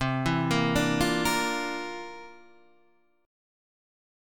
C 11th